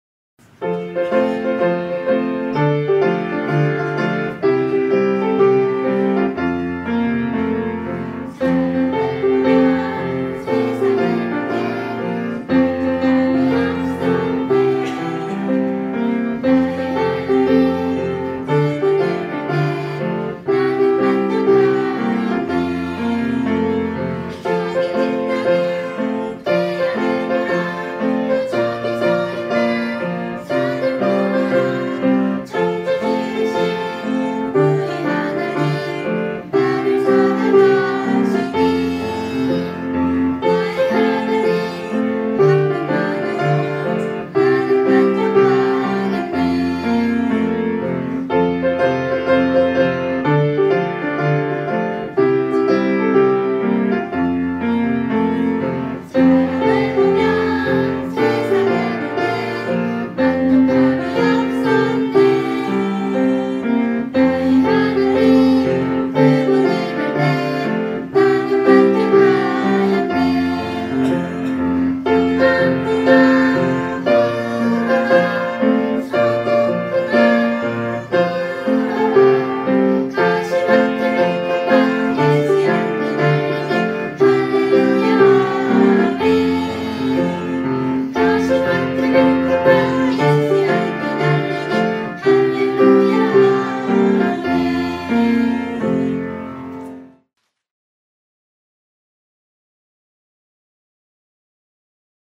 복음찬송가 듣기
0778_사람을 보며 세상을 볼 때_(부산제일성서 어린이들).mp3